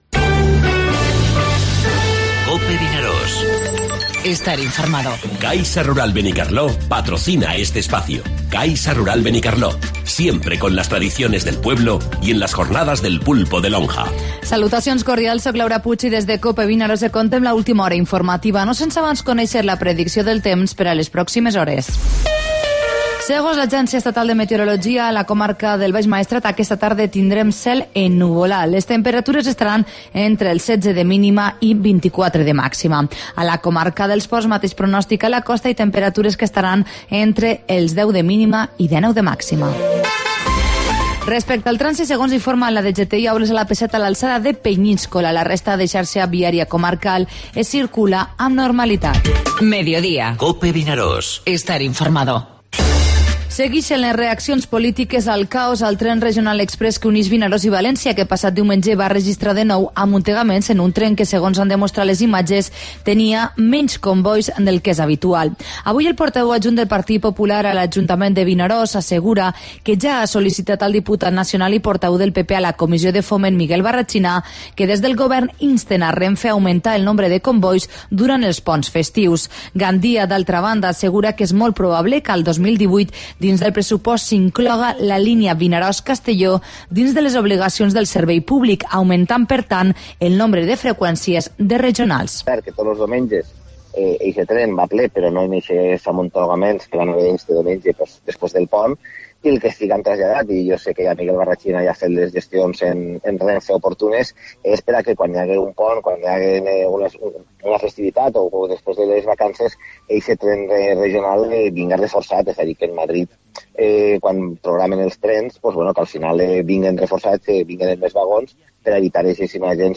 Informatiu Mediodía COPE al Maestrat (17/10/2017)